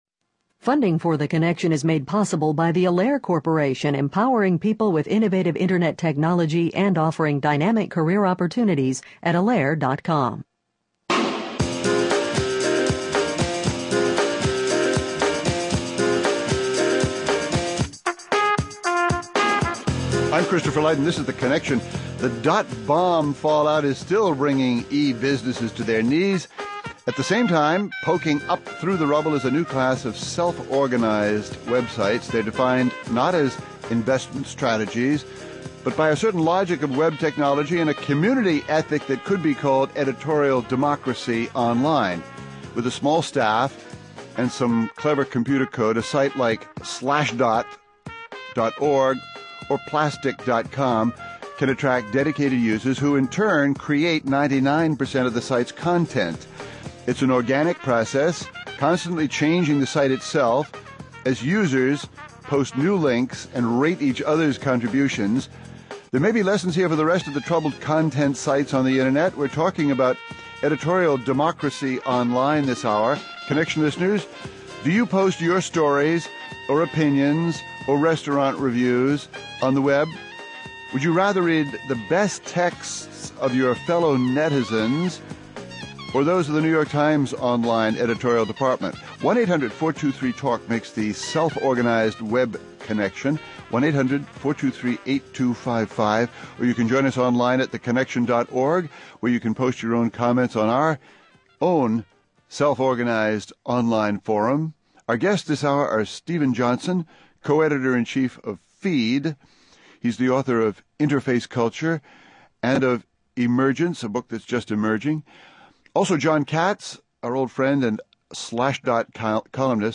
(Hosted by Christopher Lydon)